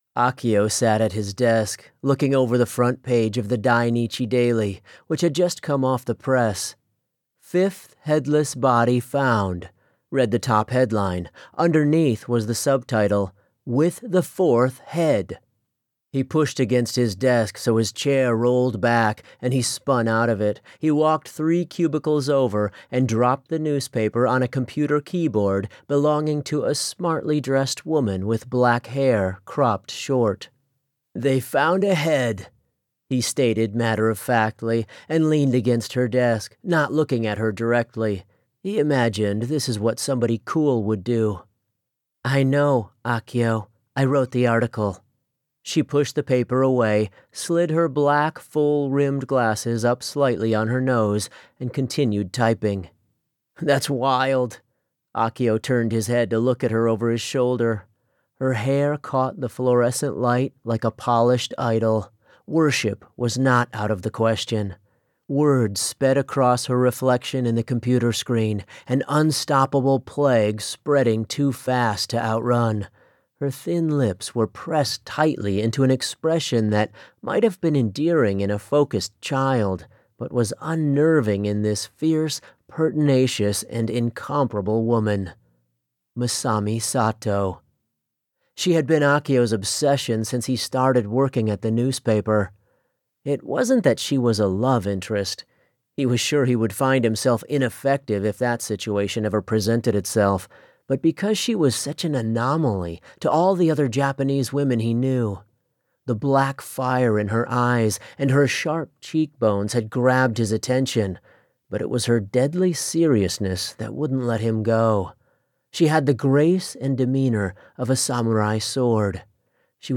Headless audiobook cover 2a sm
It has some scary moments, but it’s not as frightening as it may look. In fact, it can be pretty light and even humorous at times.